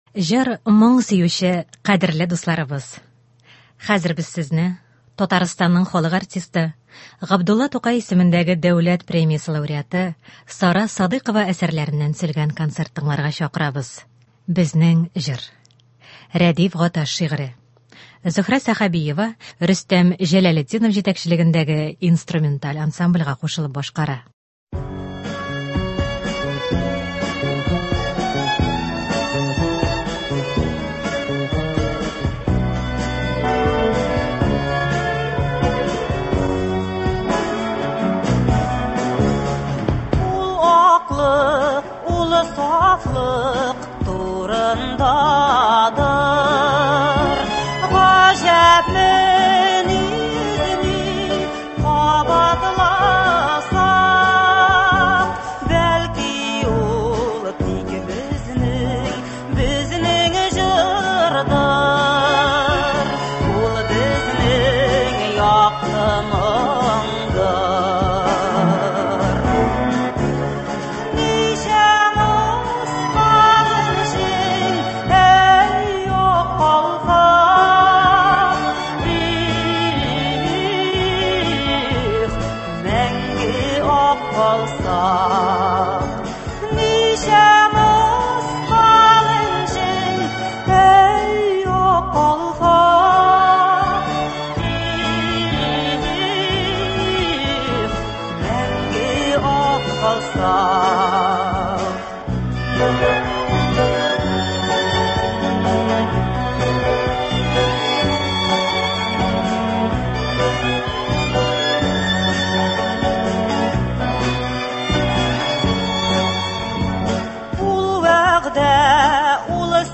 Эстрада концерты.